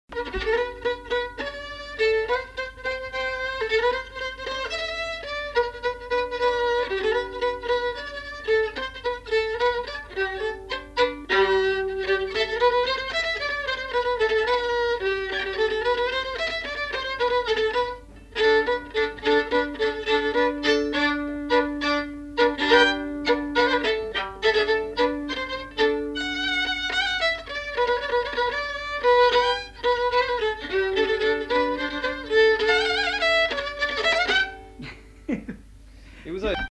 Valse
Aire culturelle : Lomagne
Genre : morceau instrumental
Instrument de musique : violon
Danse : valse